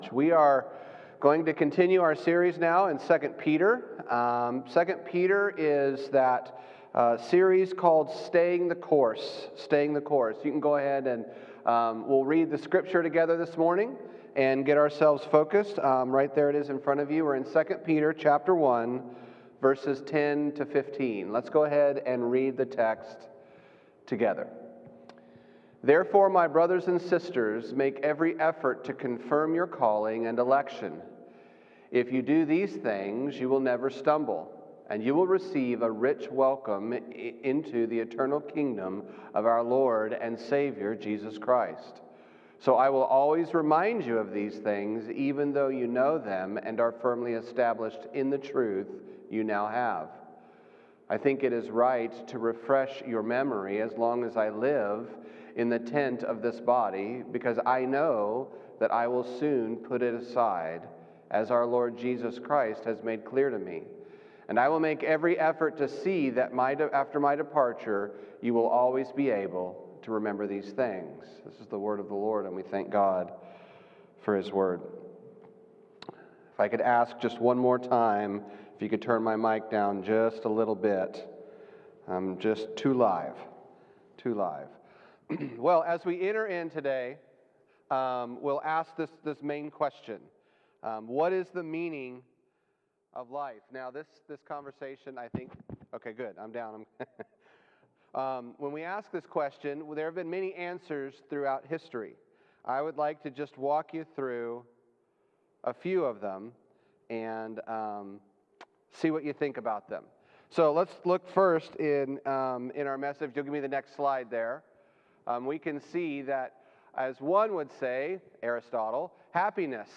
Sermon Series: 2 Peter- Staying the Course Text: 2 Peter 1:10-15 Title: Be Yourself Main Point: We need to live the life God gave us in Christ Outline: 1.